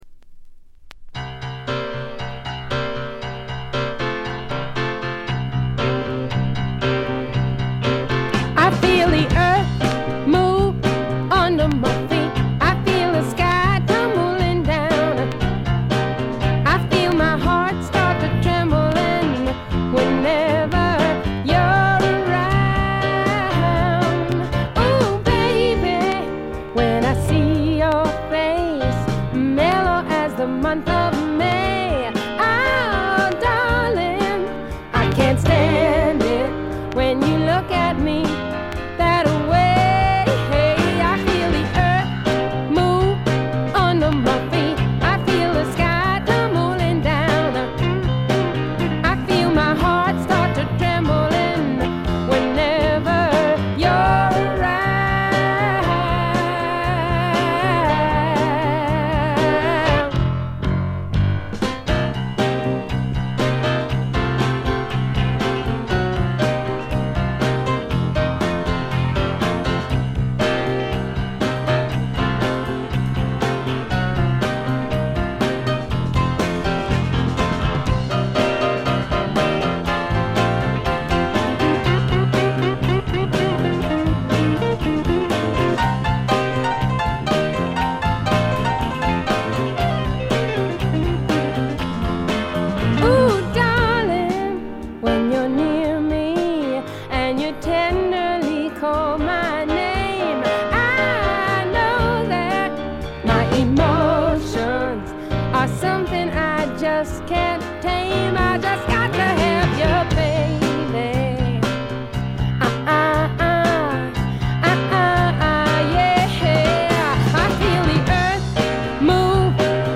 A1序盤冒頭チリプチ、B1冒頭チリプチ少し。
他は微細なチリプチ程度。
女性シンガーソングライター基本中の基本。
試聴曲は現品からの取り込み音源です。